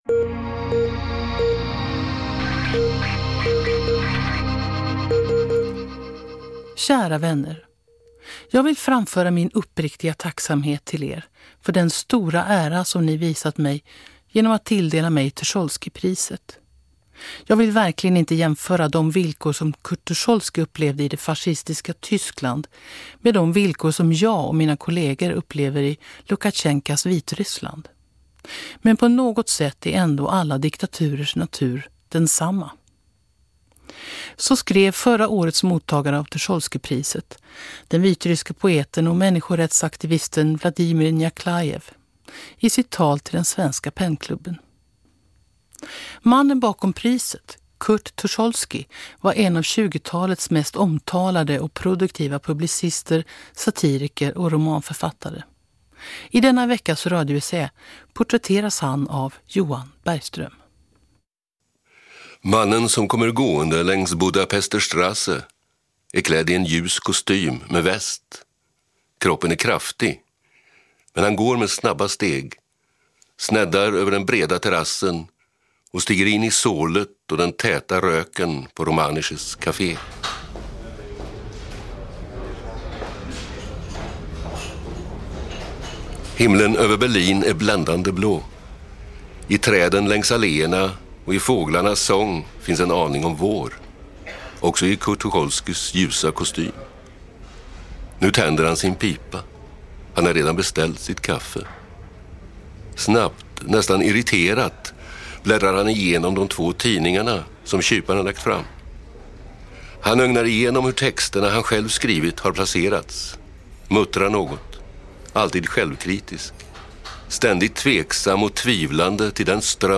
Radioessä